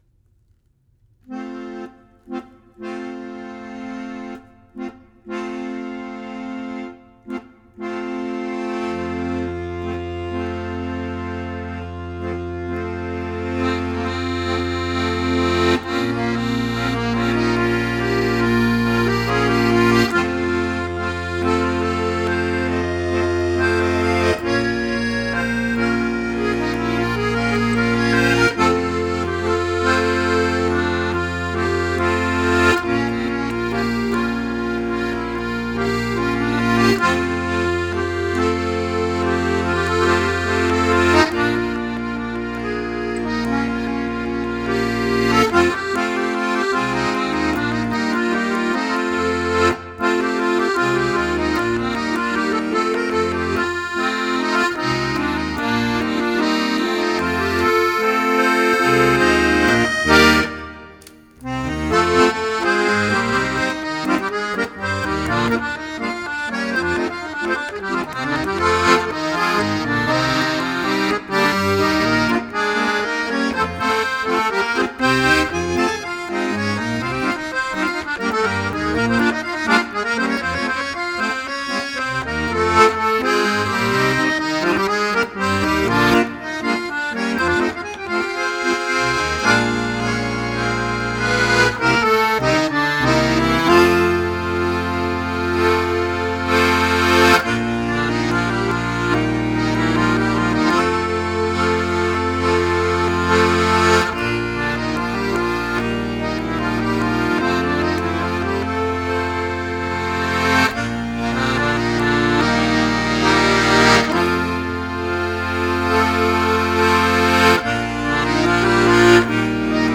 but his true love is the accordion.